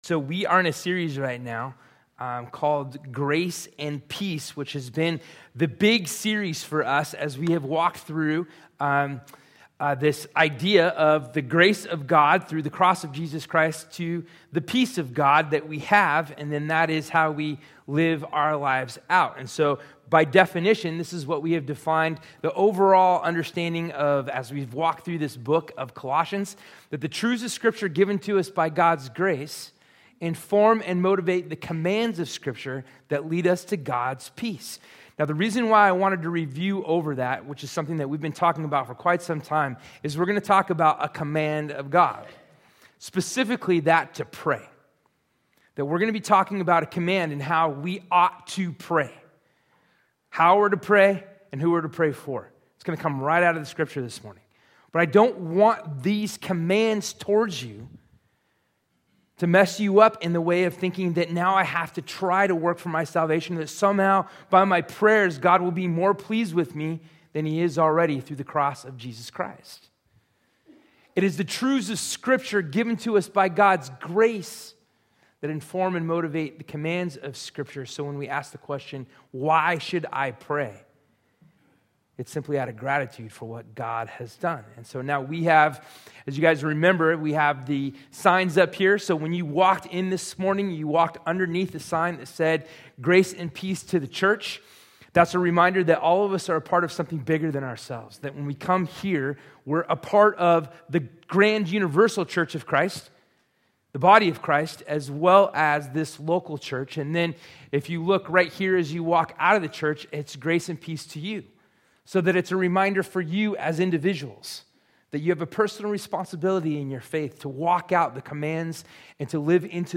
Sermon Notes Grace & Peace-The truths of scripture given to us by God’s GRACE inform and motivate the commands of Scripture that lead us to God’s PEACE.